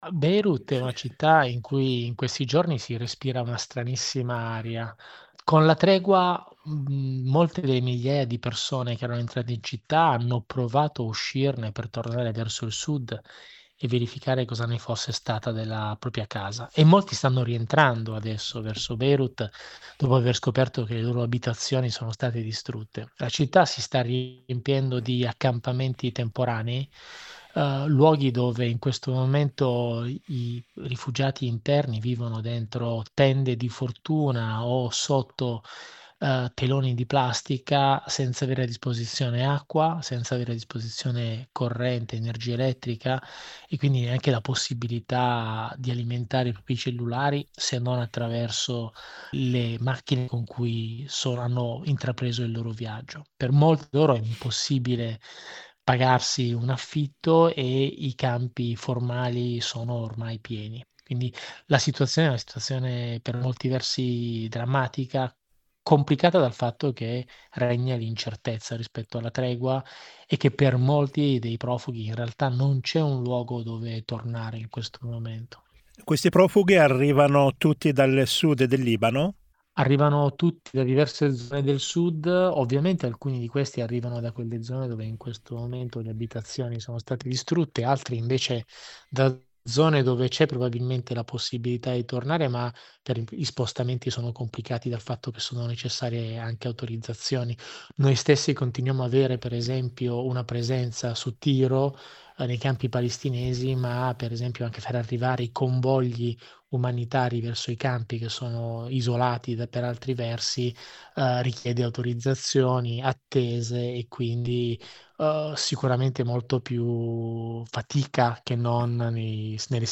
Migliaia di profughi sono ritornati a Beirut dopo aver scoperto che le loro case sono state rase al suolo dall’esercito israeliano. L’intervista